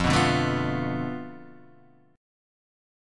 F6b5 chord